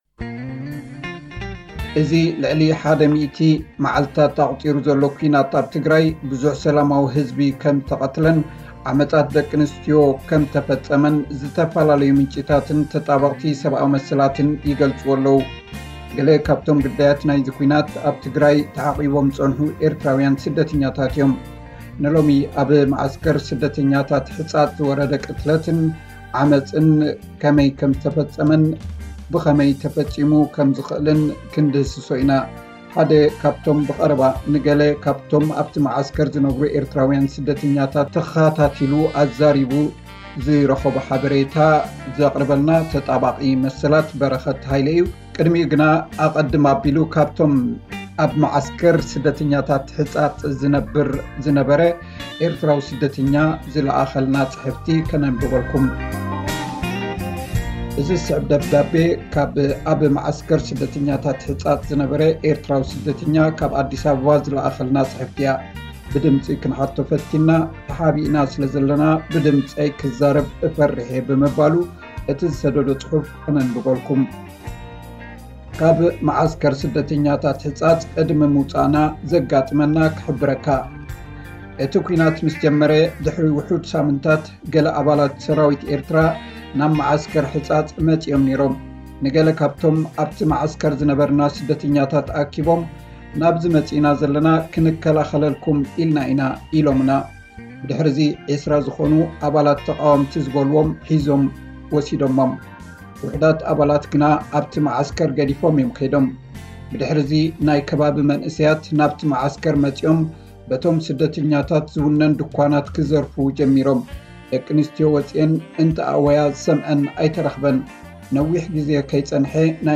ቅድሚኡ ግና ኣቐድም ኣቢሉ ካብቶም ስደተኛታት ናይ መዓስከር ስደተኛታት ዝለኣኸልና ጽሕፍቲ ከንብበልኩም።